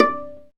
Index of /90_sSampleCDs/Roland L-CD702/VOL-1/STR_Viola Solo/STR_Vla Pizz